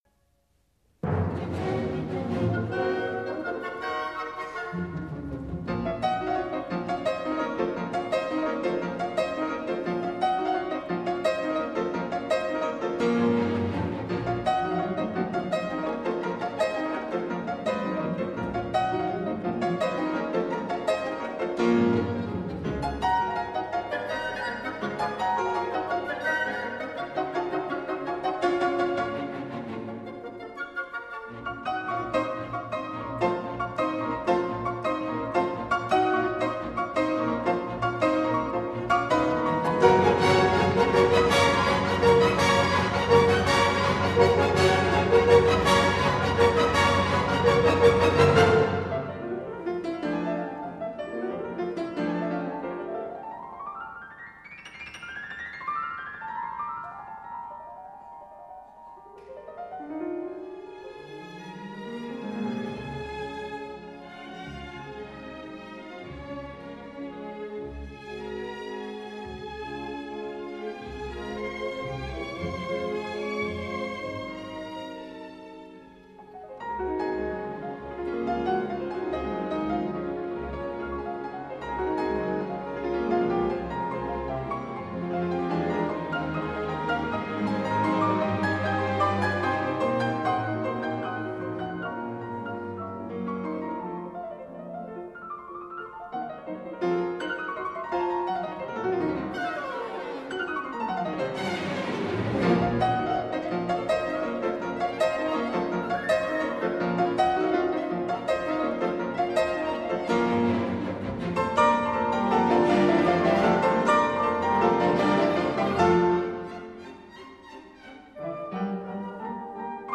录音地点：柏林（Berlin）